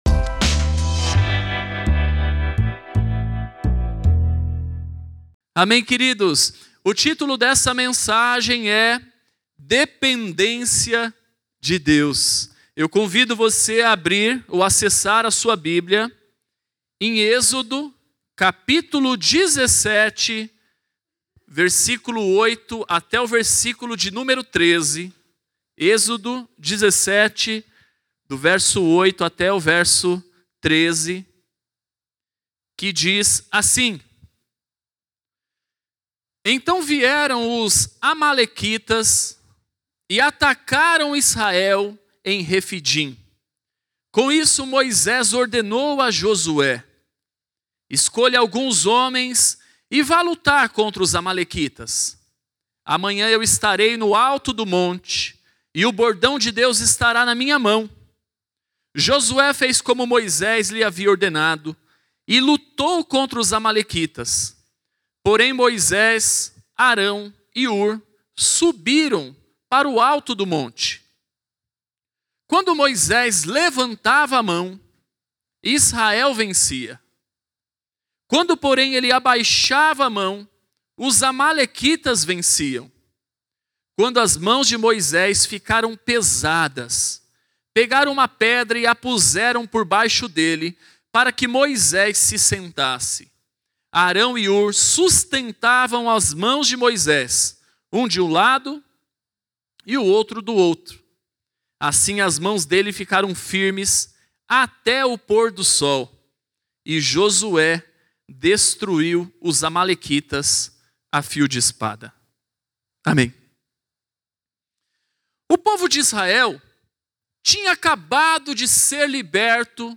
Mensagem ministrada